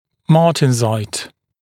[ˈmɑːtɪnzʌɪt][ˈма:тинзайт]мартенсит, мартенситный